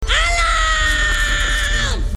alarm_3.mp3